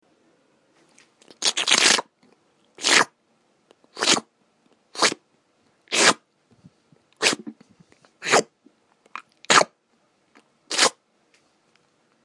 啜饮茶
描述：从杯子里啜饮和啜饮茶，吹上它来冷却。使用Sony PCMD100录制。
标签： 凉爽 吞咽 下来 喝着茶
声道立体声